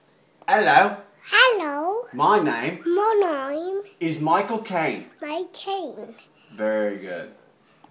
Working On Impressions: Michael Caine